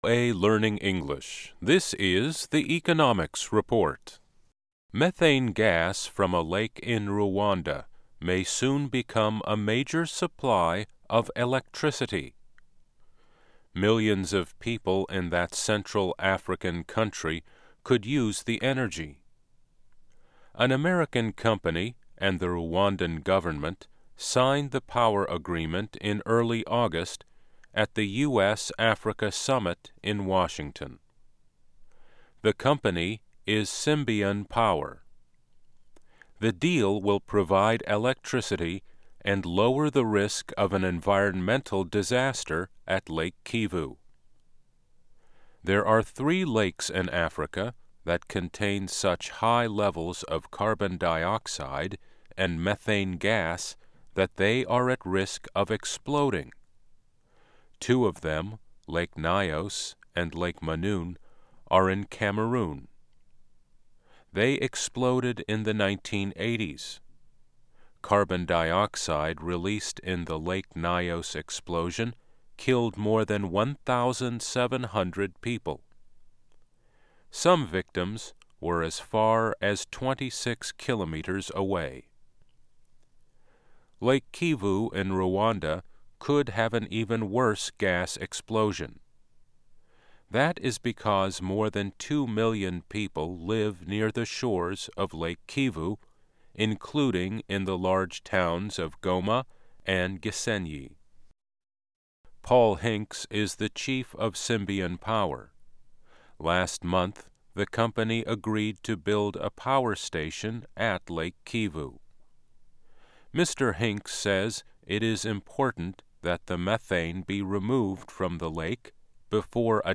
Learning English as you read and listen to news and feature stories about business, finance and economics. Our daily stories are written at the intermediate and upper-beginner level and are read one-third slower than regular VOA English.